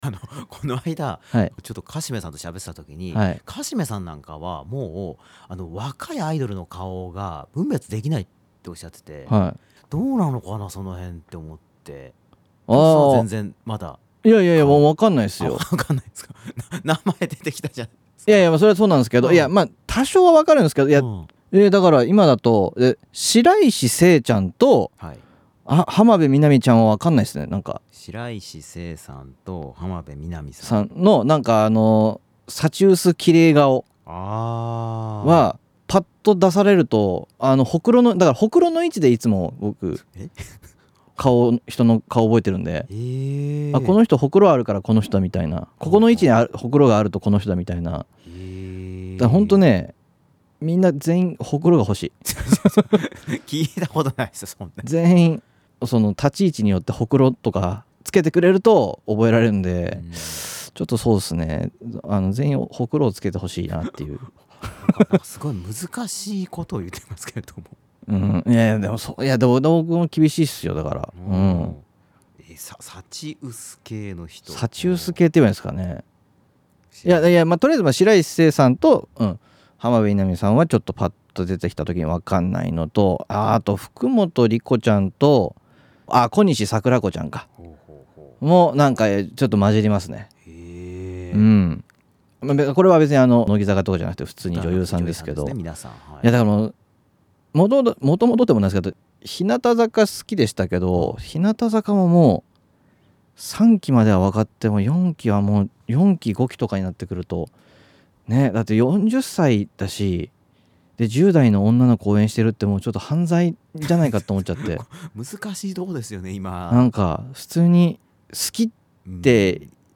本編放送の前に、放送時間の関係で泣く泣くカットしたＯＰトーク(約10分弱)をこちらで公開いたします。